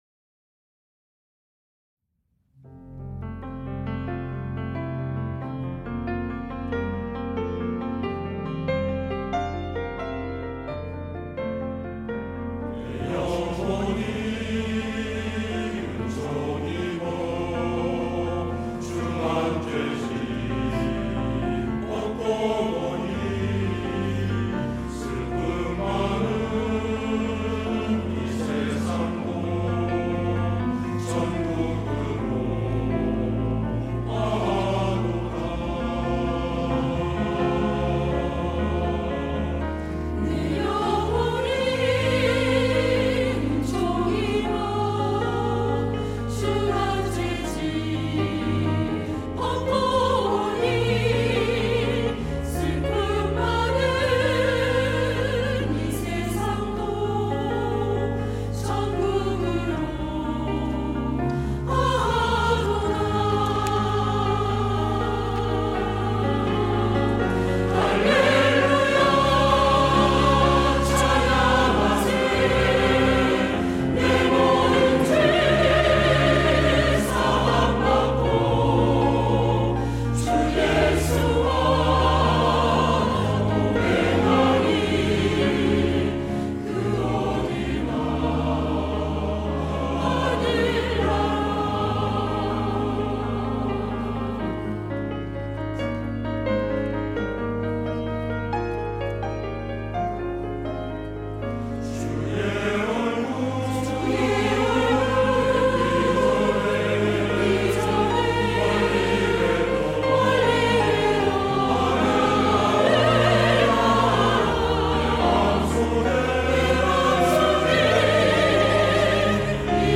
시온(주일1부) - 내 영혼이 은총입어
찬양대